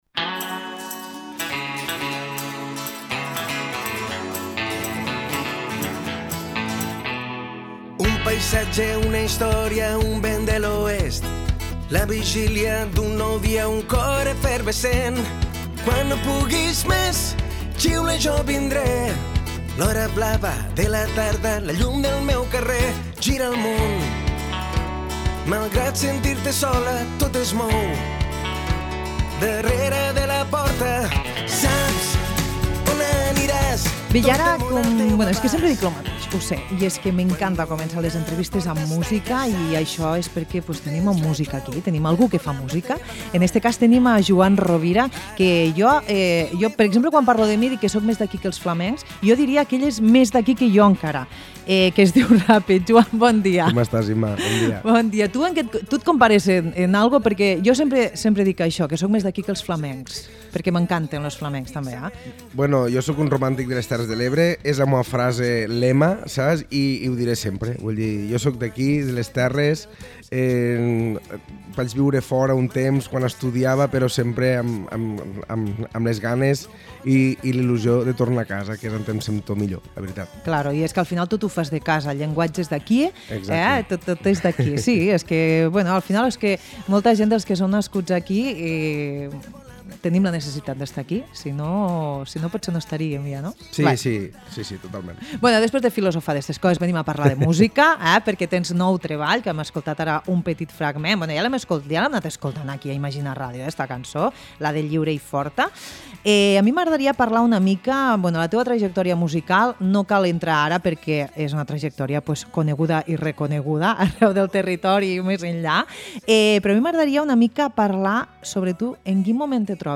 A l’àlbum trobarem algunes sorpreses, alguna de les quals ens avança en la conversa. El disc, tal com ha avançat l’artista, serà presentat aquesta primavera al Teatre Auditori Felip Pedrell de Tortosa.